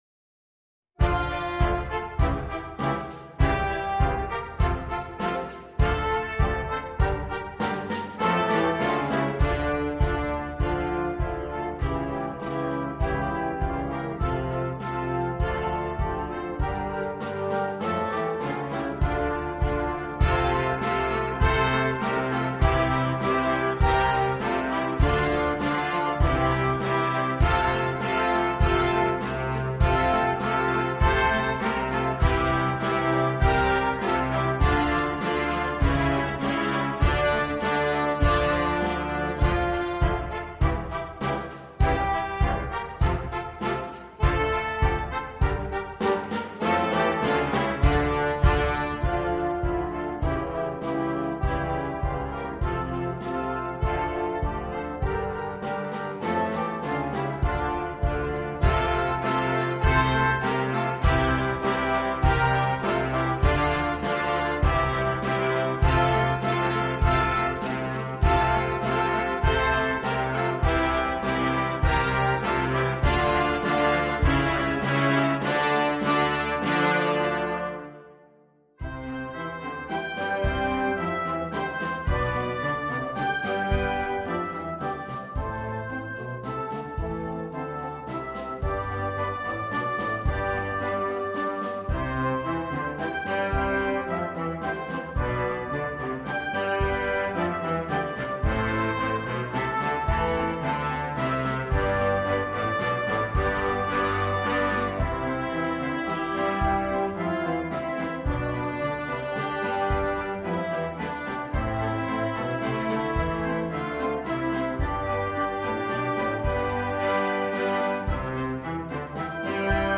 Besetzung: Flex Ensemble